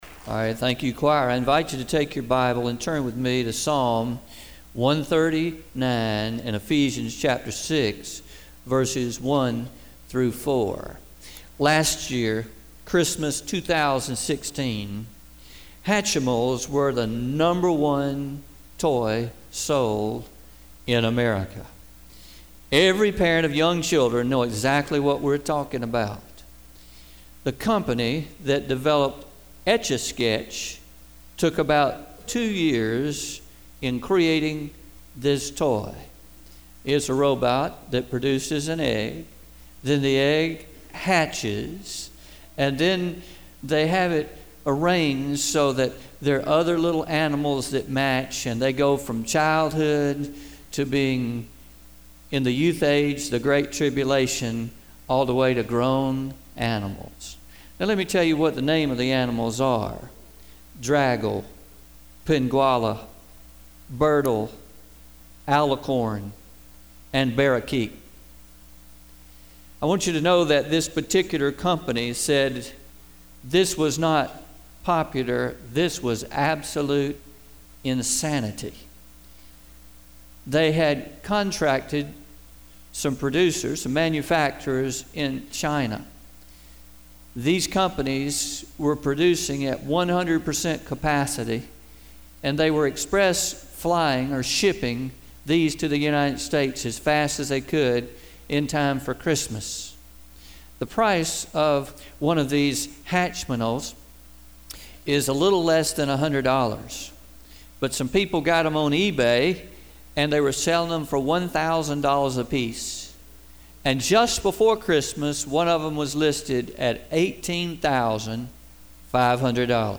Message from Sunday morning August 20, 2017: A Child – God’s Gift